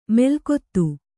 ♪ melkottu